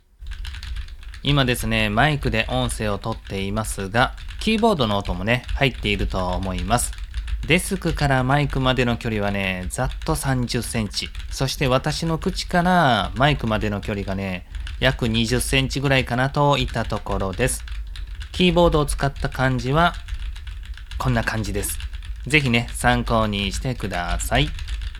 ※マイクゲインはmax100の状態です。
1：ノイズゲートON状態（デフォ設定）
2：キーボードからマイクまで約30cm
3：クチからマイクまで約20cm
そこそこ音が入る事は間違いないですね。